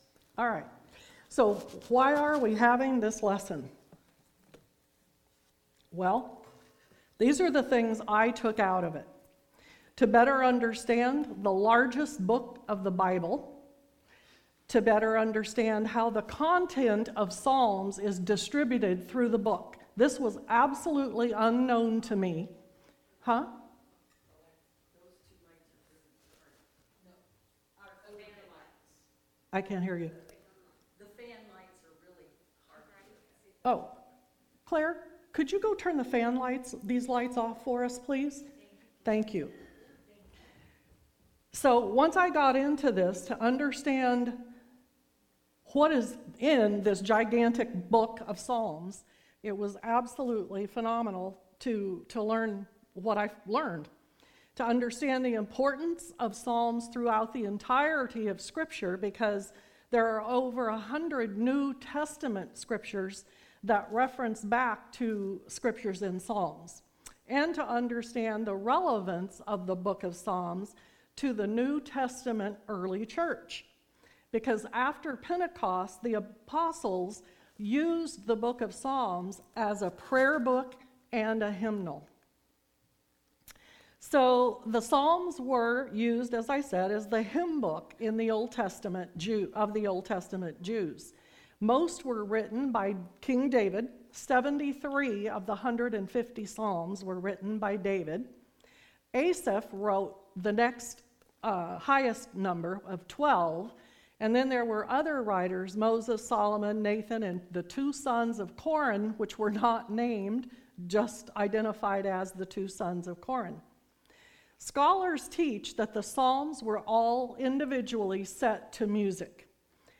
Morning Sermons